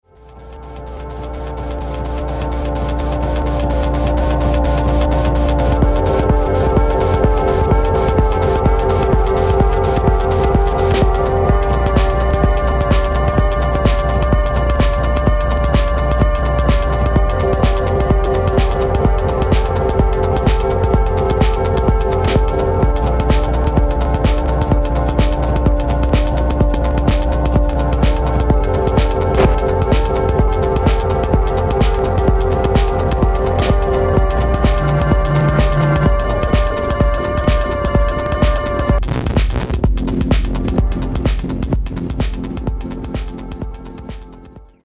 House Techno Detroit Dub